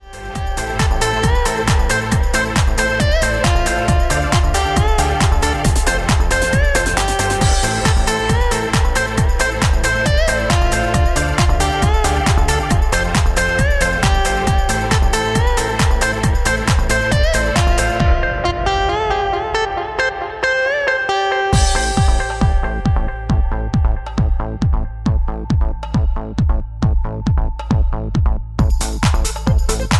Am
MPEG 1 Layer 3 (Stereo)
Backing track Karaoke
Pop, 1990s